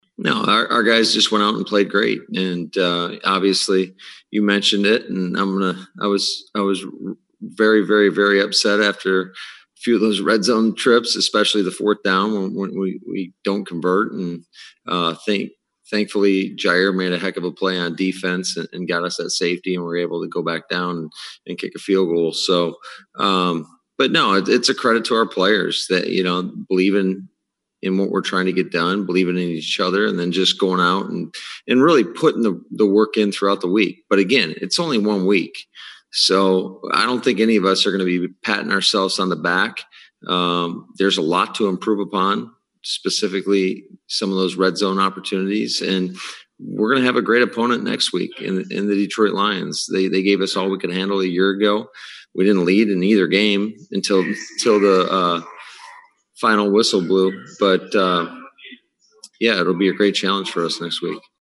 Afterwards, the Packers paraded LaFleur and three players before the zoom camera for post-game press conferences.